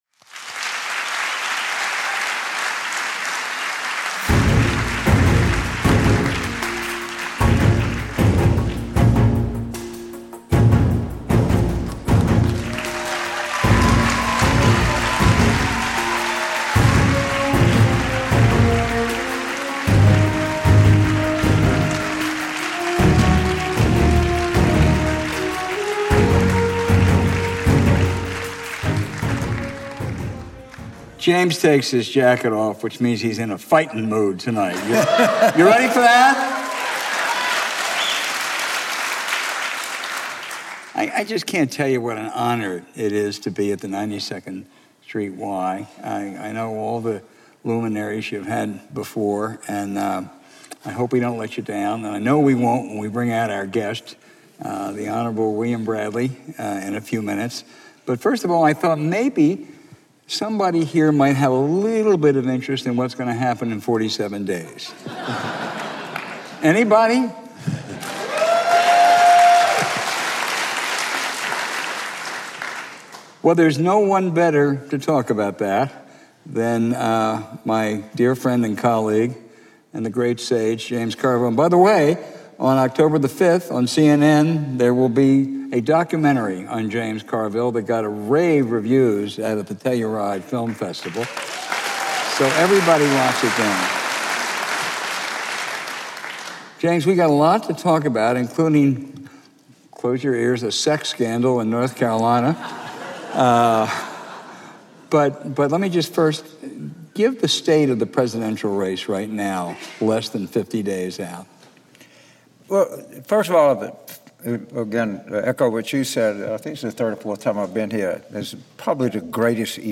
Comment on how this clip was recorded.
Live at 92NY